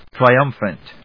音節tri・um・phant 発音記号・読み方
/trɑɪˈʌmf(ə)nt(米国英語)/